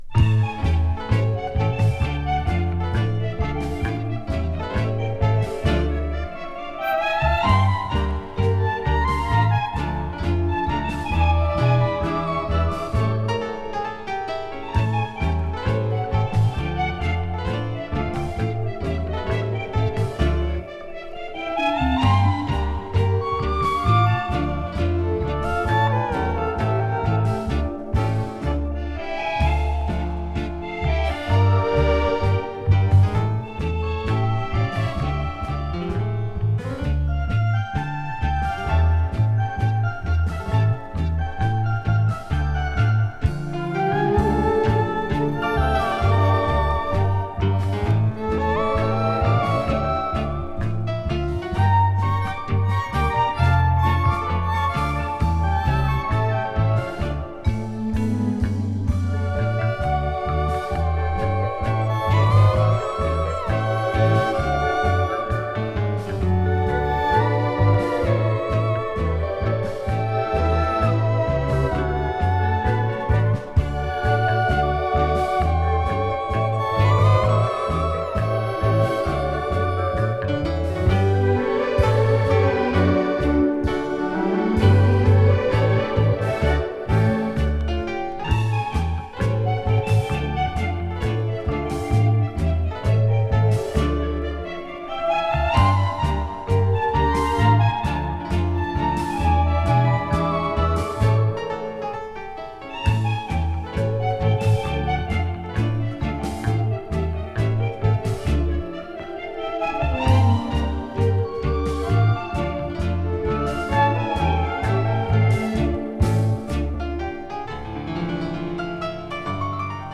три танго